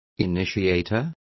Complete with pronunciation of the translation of initiators.